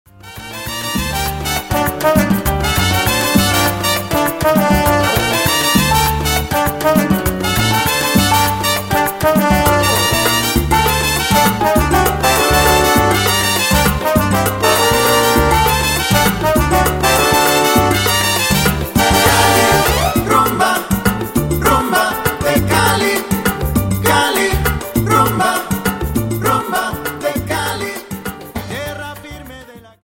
Dance: Salsa 50